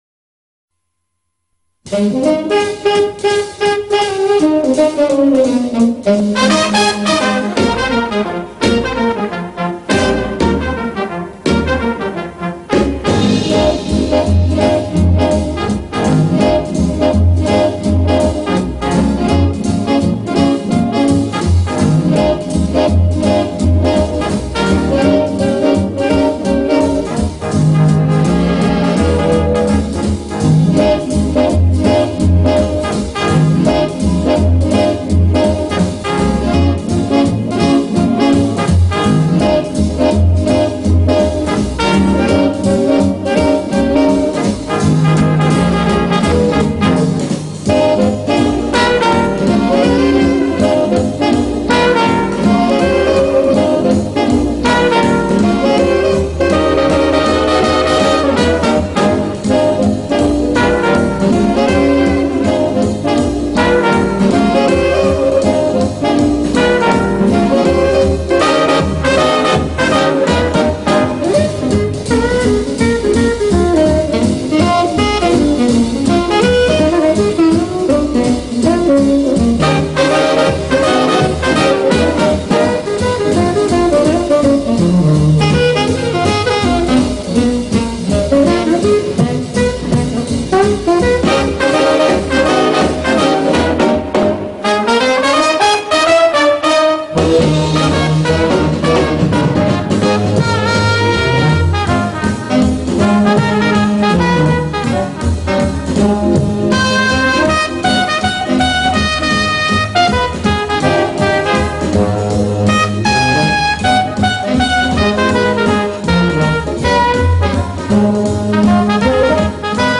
Época y 3 características del SWING
Con ritmo bailable, música comercial. Interpretada por las BIGBANDS, con una sección rítmica de 4 intérpretes, 8 instrumentos de viento metal, 5 de viento madera y a veces cantante.